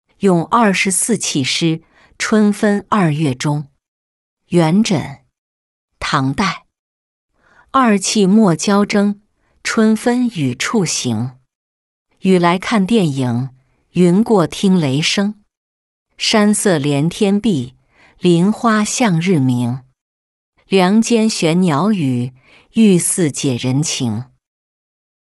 咏二十四气诗·春分二月中-音频朗读